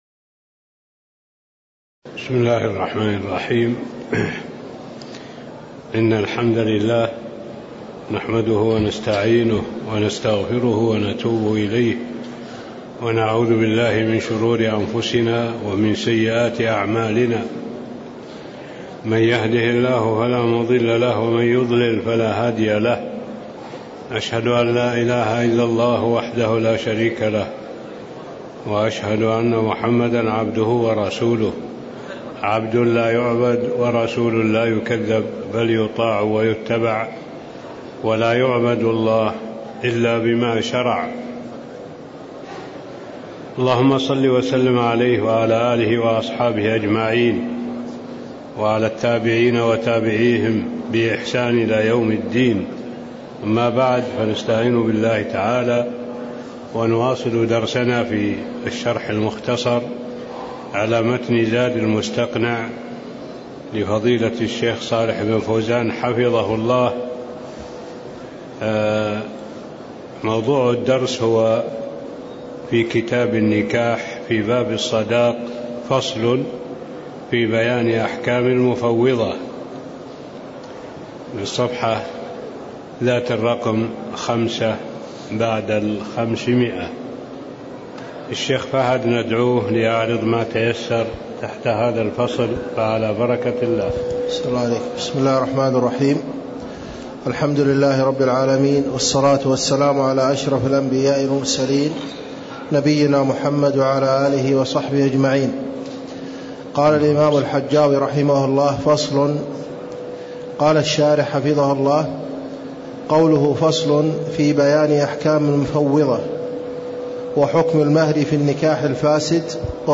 تاريخ النشر ١١ رجب ١٤٣٥ هـ المكان: المسجد النبوي الشيخ: معالي الشيخ الدكتور صالح بن عبد الله العبود معالي الشيخ الدكتور صالح بن عبد الله العبود فصل في بيان أحكام المفّوضة (04)